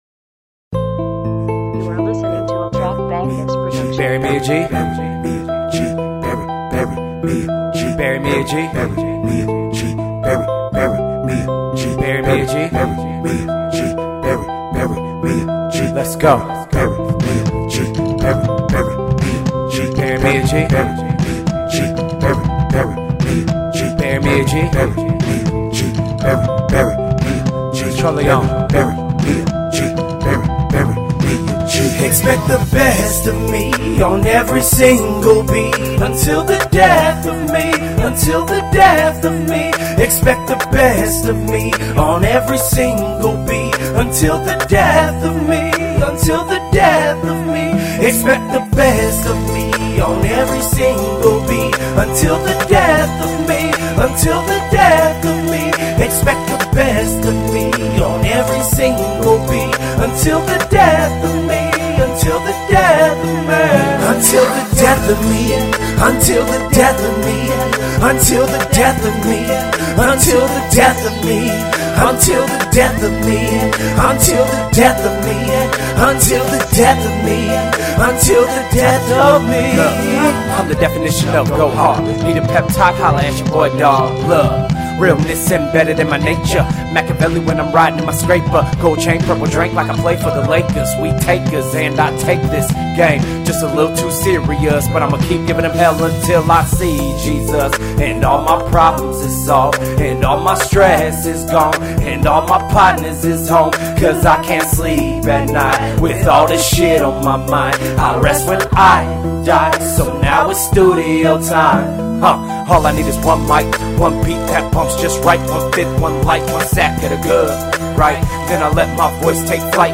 Hiphop
Description : OHIO Hip-Hop Muzik!!!